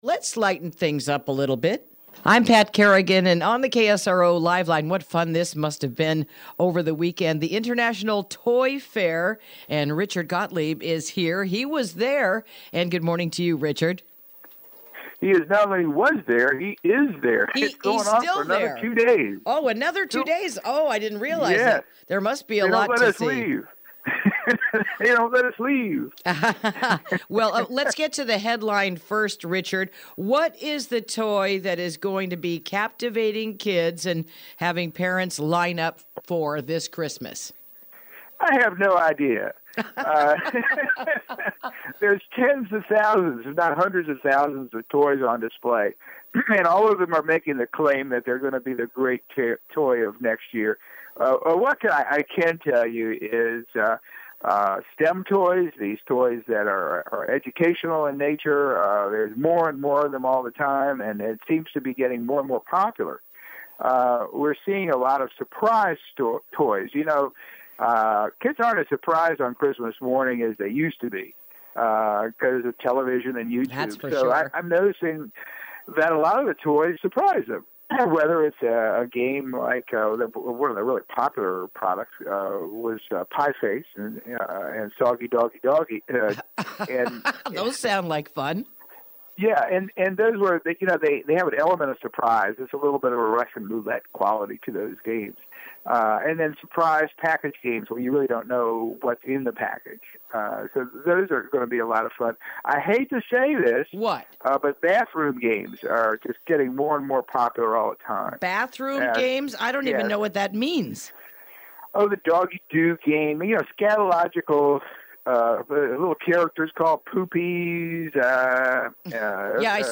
Interview: What Are Going to Be the Biggest Toys of the Year?